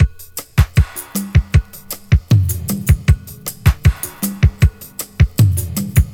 • 78 Bpm Drum Loop Sample F Key.wav
Free drum beat - kick tuned to the F note. Loudest frequency: 1767Hz
78-bpm-drum-loop-sample-f-key-utQ.wav